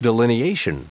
Prononciation du mot delineation en anglais (fichier audio)
Prononciation du mot : delineation